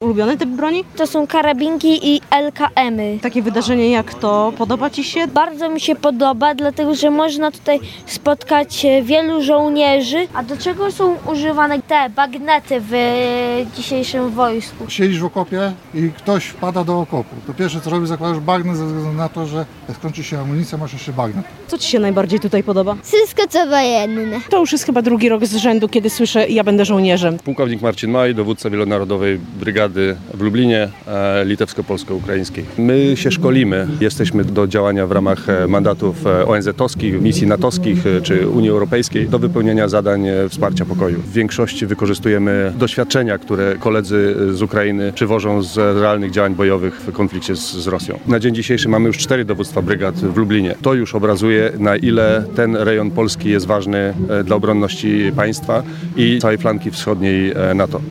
Pokaz broni, koncert orkiestry wojskowej czy klasyczna wojskowa grochówka – Brygada Wielonarodowa LITPOLUKRBRIG świętuje 10. rocznicę utworzenia i z tej okazji zorganizowała piknik rodzinny w Lublinie.
– Takie wydarzenie bardzo mi się podoba, dlatego że można tutaj spotkać wielu żołnierzy. Podoba mi się tu wszystko, co wojskowe – mówią najmłodsi uczestnicy pikniku.